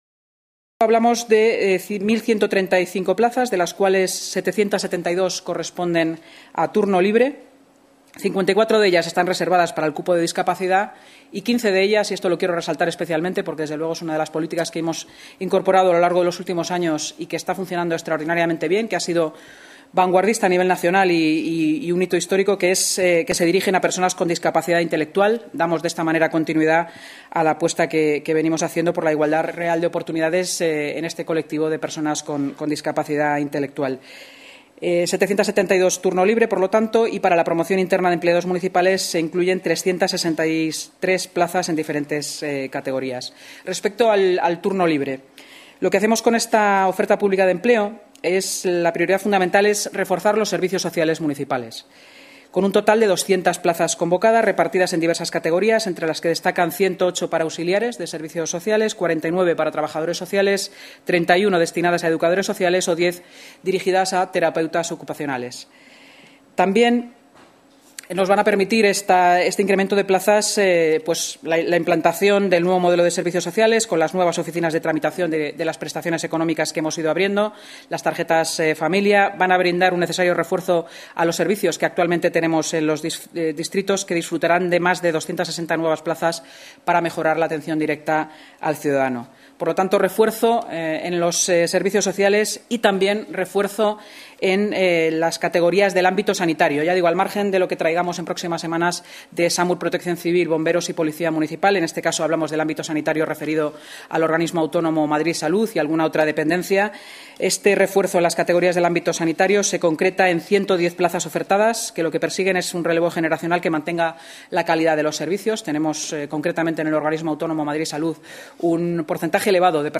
Nueva ventana:La vicealcaldesa de Madrid y portavoz municipal, Inma Sanz